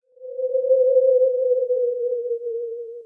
se_girl_wowo.wav